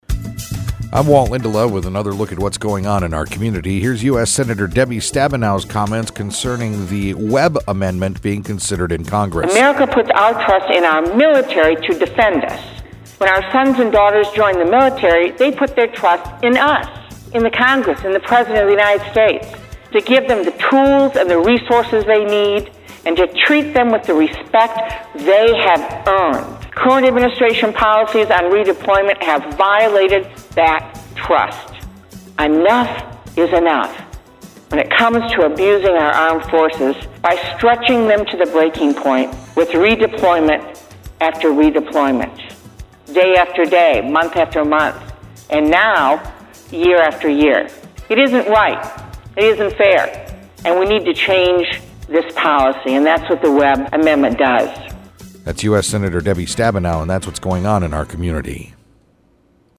Debbie Stabenow, US Senator, Michigan – Discussion of the Webb amendment